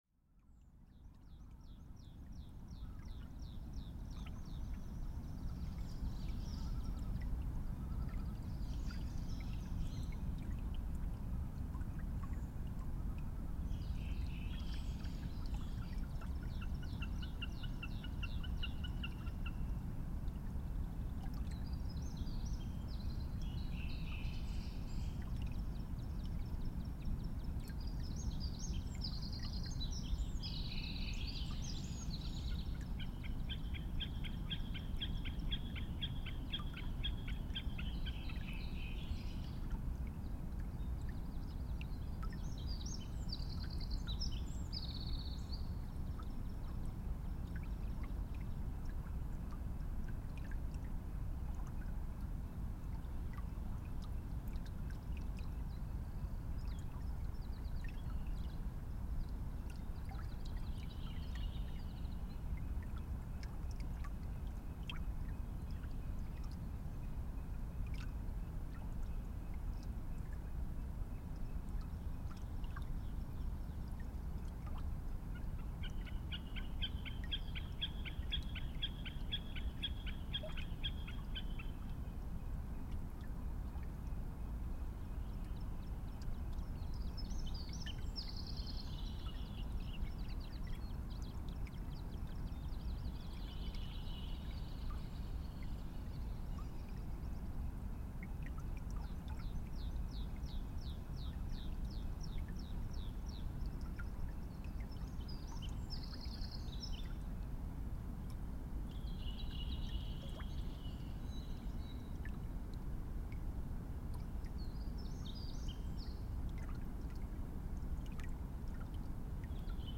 Through the valley flows a small creek, Vesturdalsá, on its way to the river Jökulsá á Fjöllum. The first week in June 2014 I arrived there on a foggy night and placed the microphones close beside the creek. The soundscape in the fog was particular. The rumble sound from Jökulsá River about 3 km away, filled the air with extreme murky mysterious power. But all around me was a beautiful bird song that followed me in to the sleep.
Quality headphones are recommended while listening at low level.
Fjölskrúðugur fuglasöngur kom úr öllum áttum, en loftið var þrungið drungalegum drunum frá Jökulsá á Fjöllum í austri.